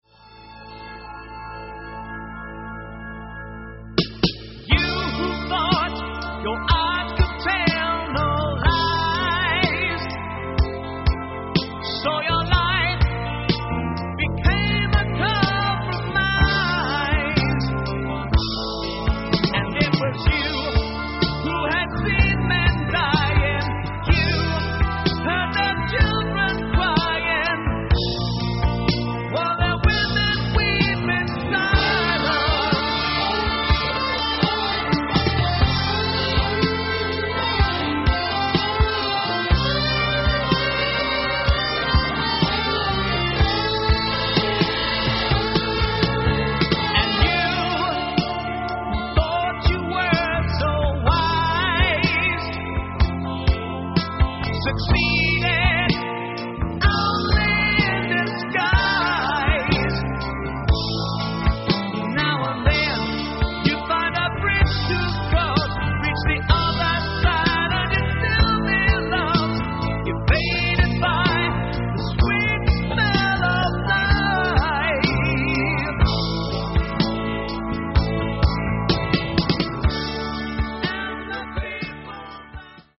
The band was very experimental with the recording.
Roundhouse Recording Studios, London
Bass, Guitar, Mellotron, Electric Piano, Vocals
Drums, Percussion, Vocals
Lead Guitar, Acoustic Guitar, 12 String Acoustic Guitar